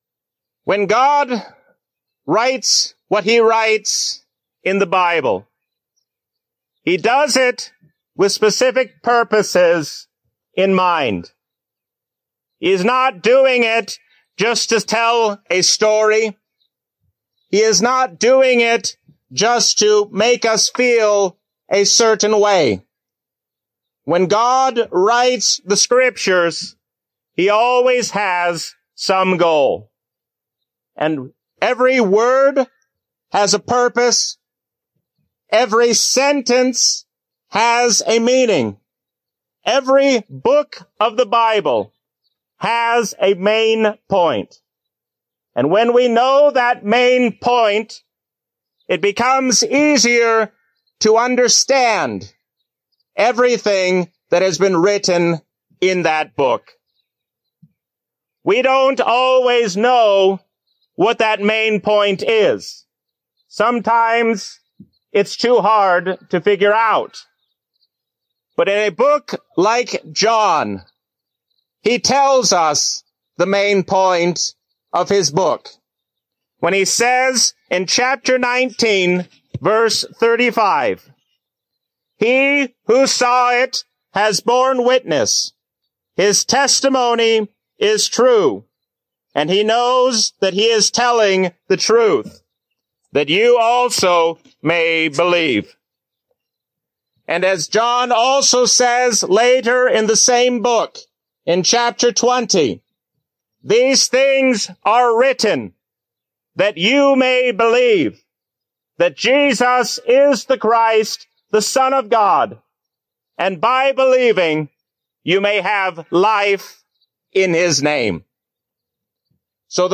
A sermon from the season "Trinity 2023." Listen to Jesus and do what He says, because He is your loving Lord.